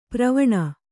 ♪ pravaṇa